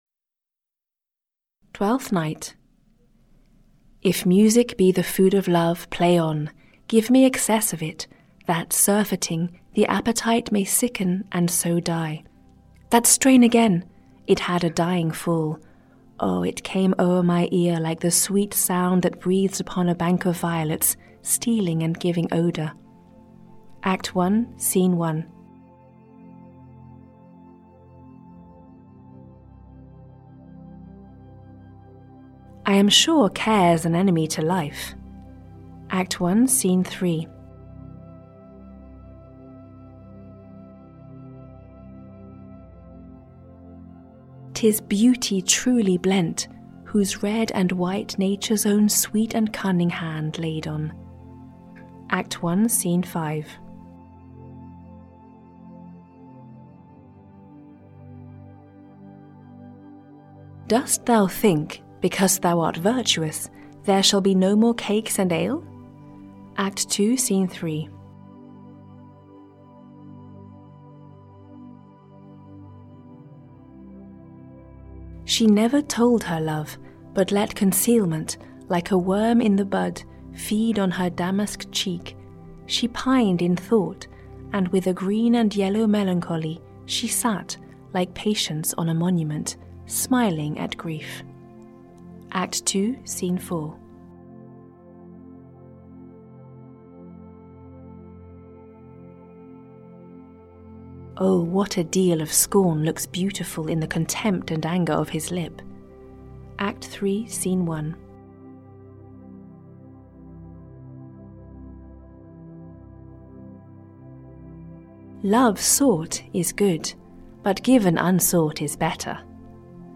Audio knihaBest Quotes by William Shakespeare (EN)
Ukázka z knihy